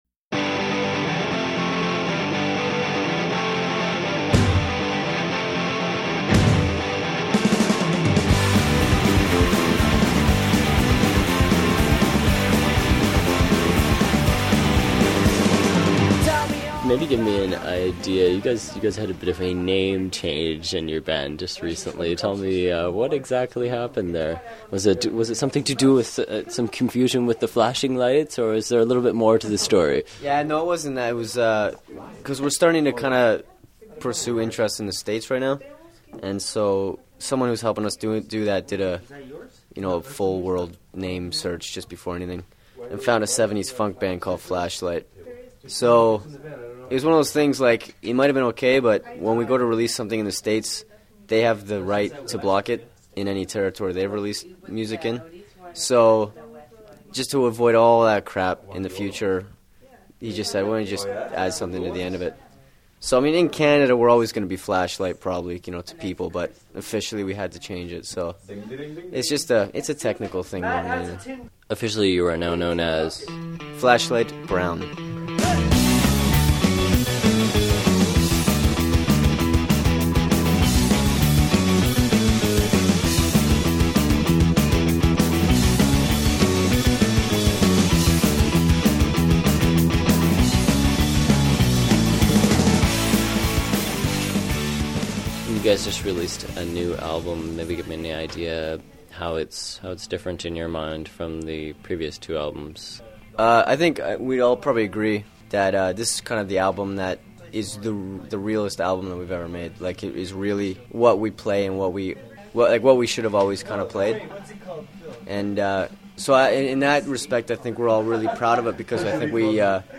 !earshot interview with Flashlight Brown: